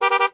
honk2.ogg